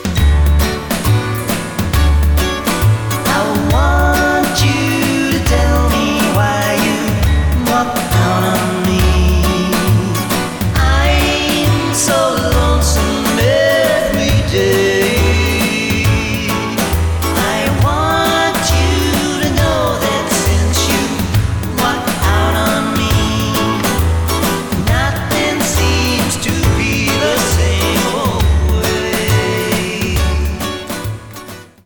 cover version